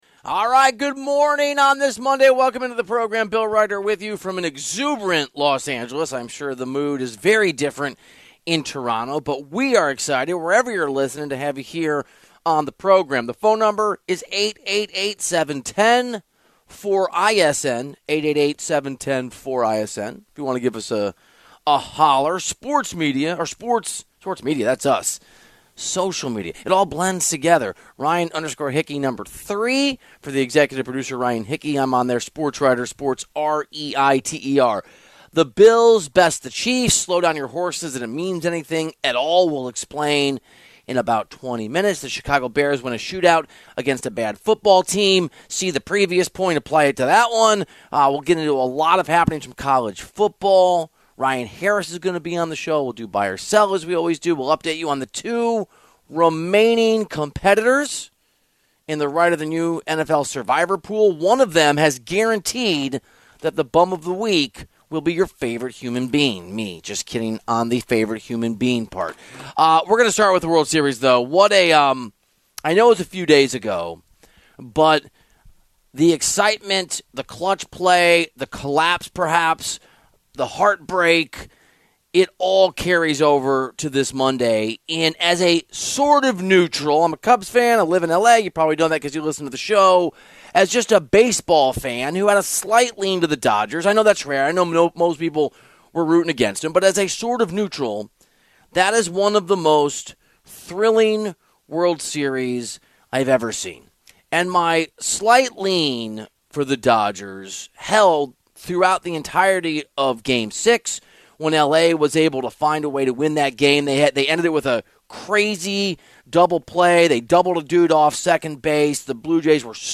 Bill Reiter is the host of Reiter Than You, which airs Monday to Friday from 10 a.m. to 12 p.m. ET on Infinity Sports Network.
Hour two kicked off with Super Bowl champion Ryan Harris joining the show to discuss if Buffalo finally found the blueprint to beat the Chiefs in the playoffs and if the Seahawks are legit.